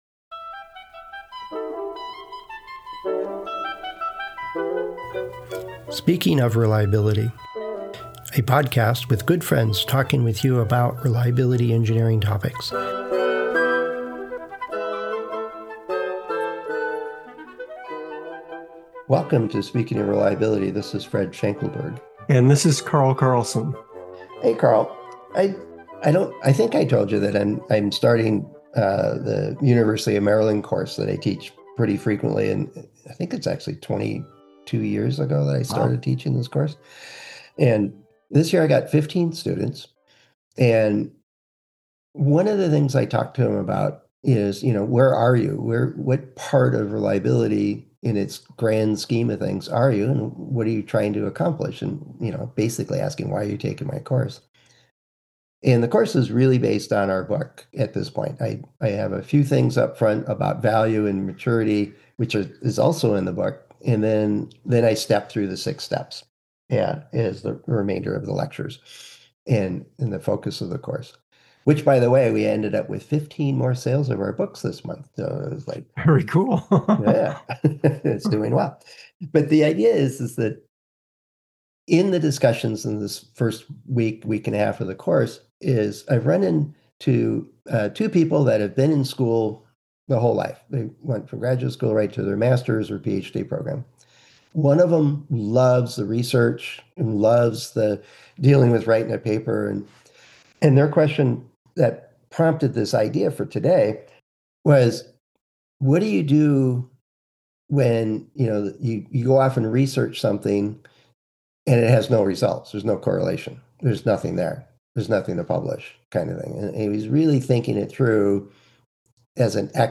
Good friends talking about your reliability engineering questions